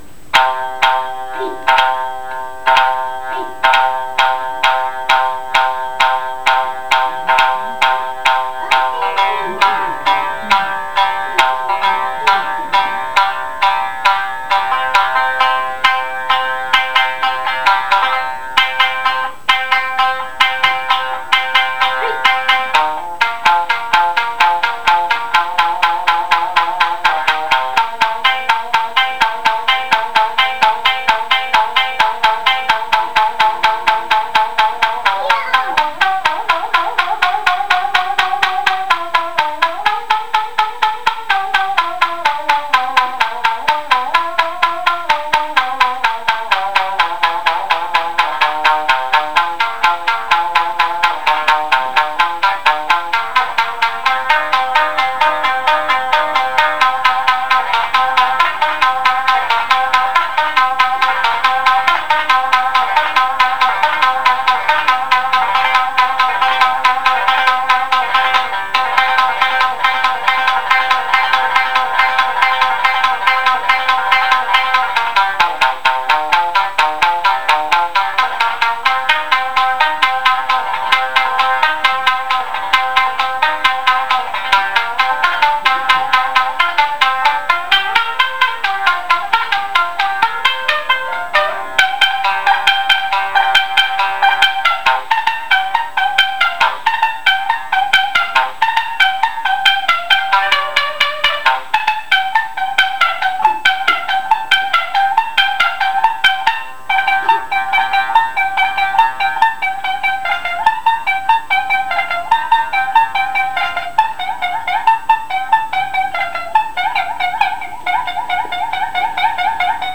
zyonkara in kanagi.wav